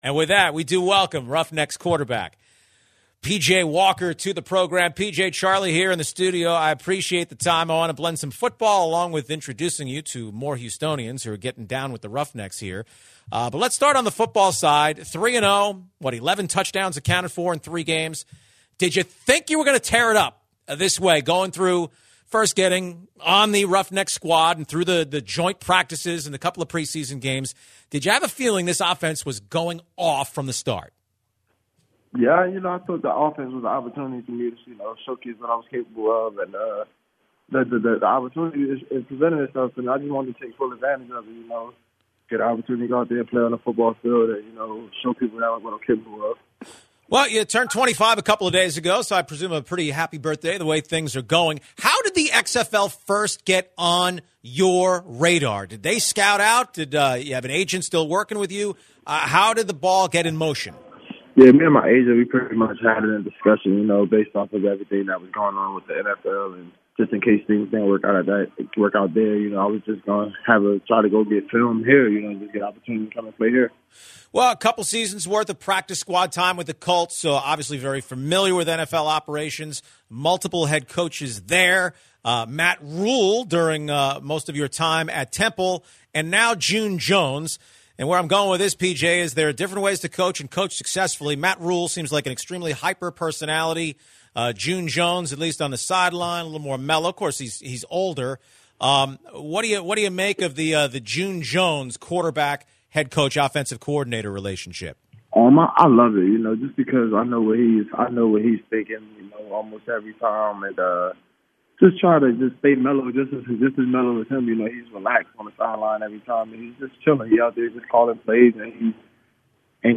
Houston Roughnecks quarterback P.J. Walker joins the show ahead of the Roughnecks-Renegades matchup on Sunday.